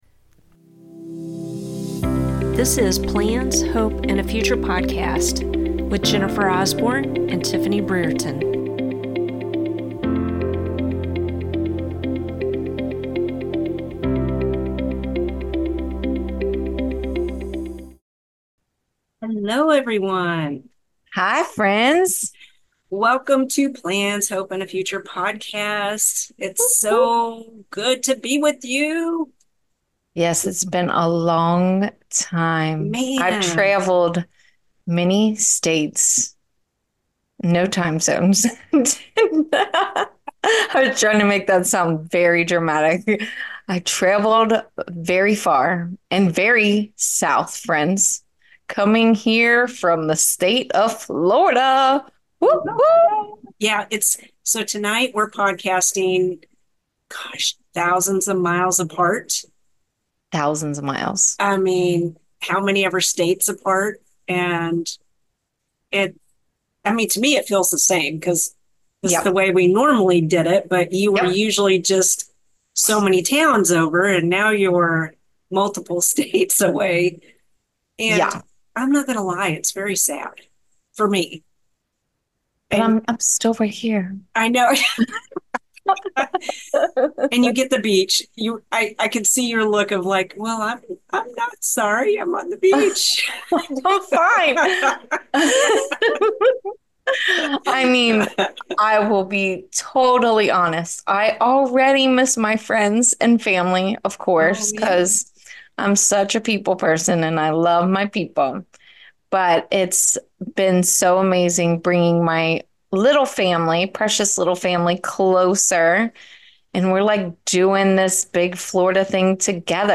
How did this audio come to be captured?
Coming to you from Florida and Kentucky! Our first episode after the big move. We also talk about what it means to share your story and how important YOU are.